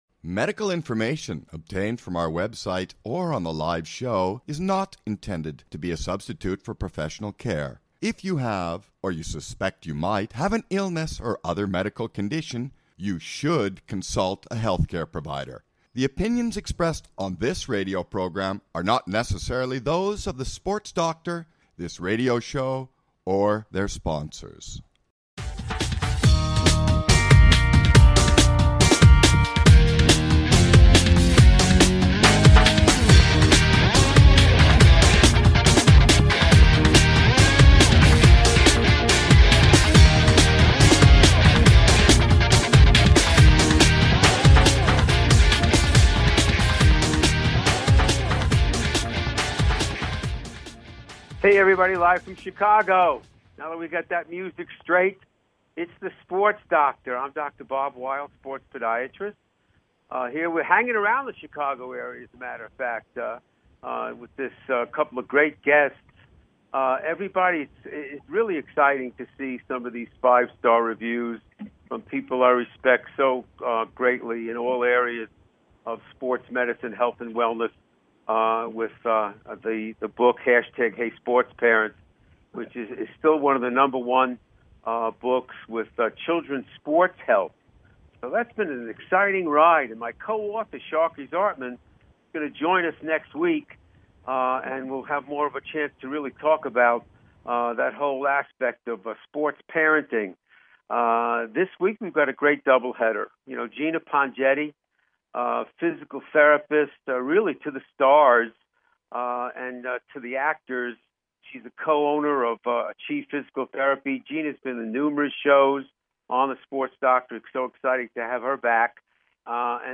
LIVE from Chicago!